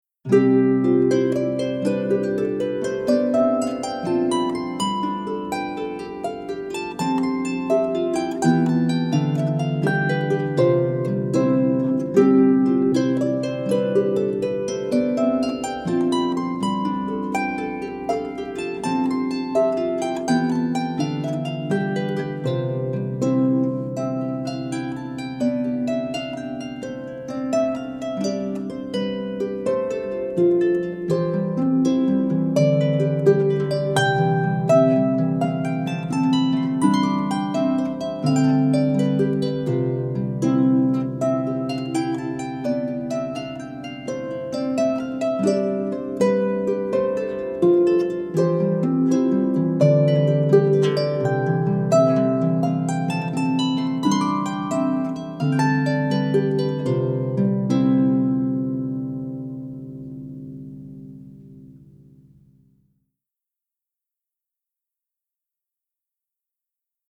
for solo lever or pedal harp. This lively tune
This tune is lively even when played at moderate speed.